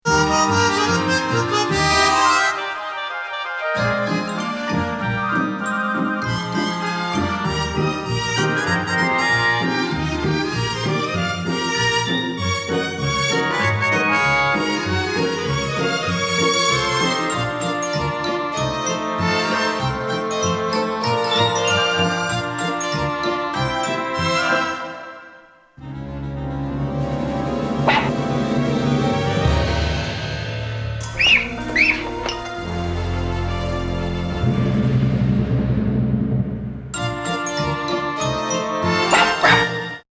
soundtrack up-tempo playful symphony orchestra